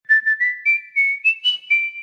• Категория: Рингтон на смс